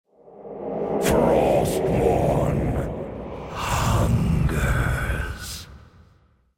(it also whispers every X sec random but gotta replace voice since i used this:)